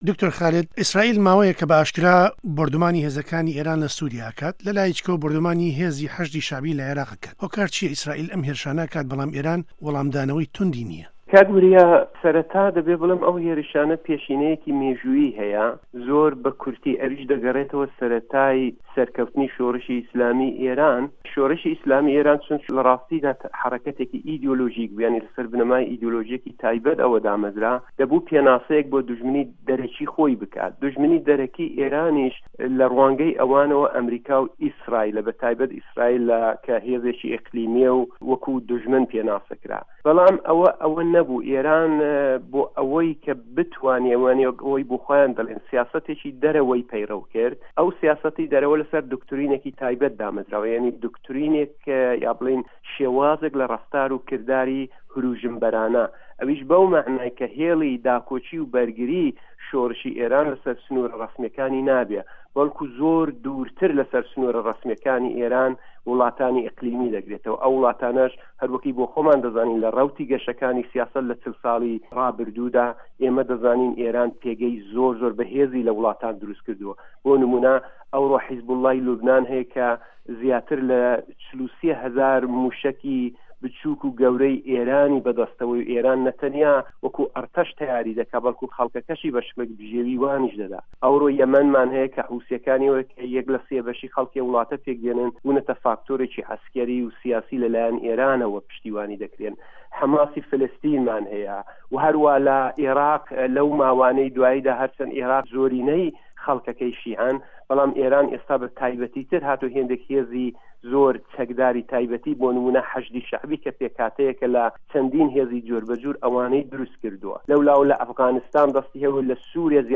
گفتووگۆ